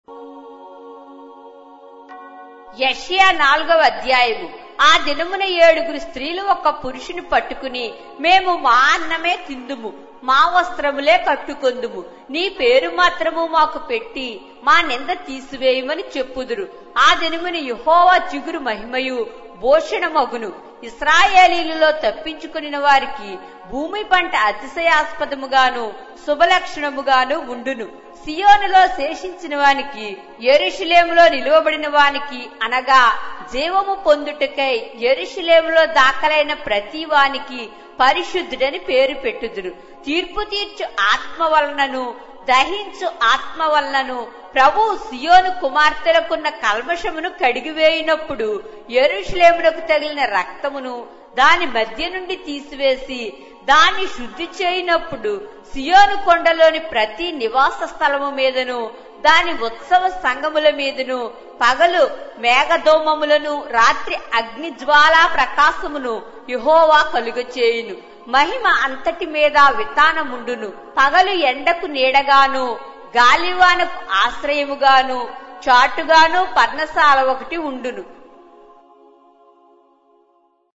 Telugu Audio Bible - Isaiah 43 in Irvml bible version